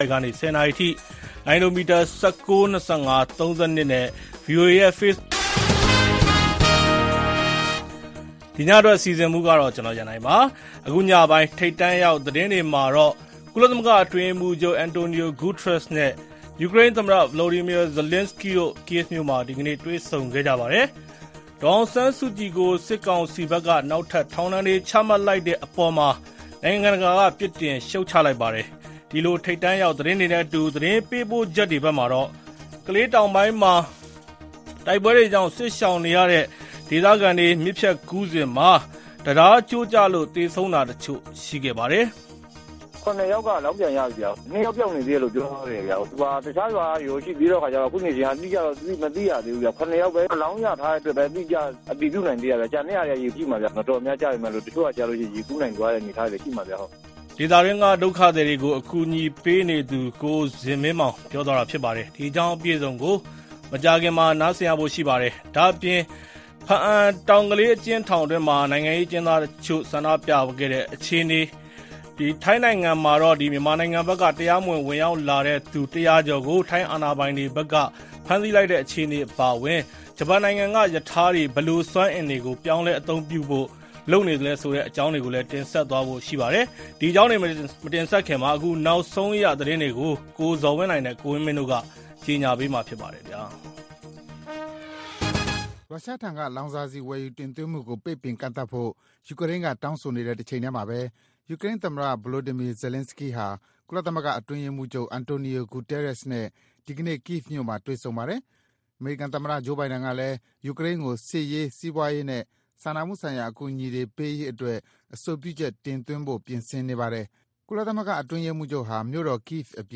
ဗွီအိုအေ ကြာသပတေးည ၉း၀၀-၁၀း၀၀ နာရီ ရေဒီယို/ရုပ်သံလွှင့်အစီအစဉ်